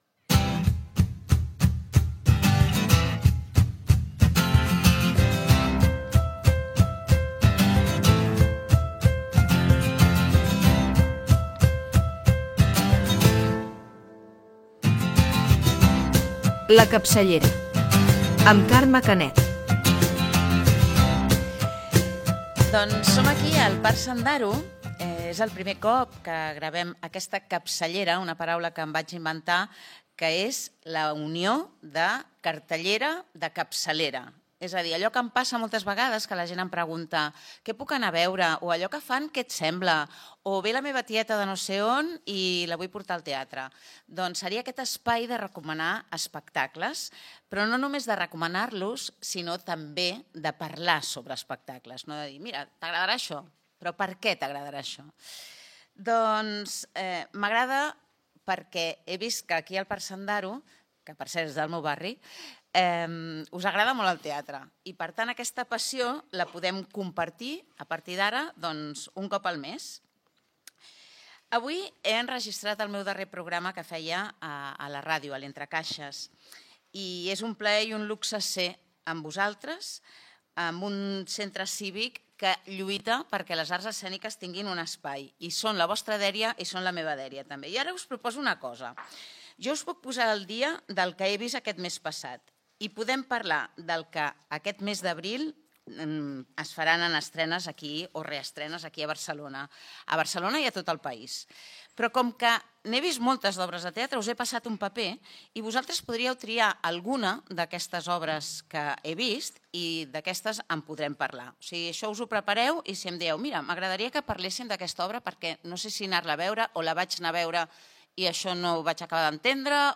Primera edició del programa. Careta, explicació del títol i objectiu del programa.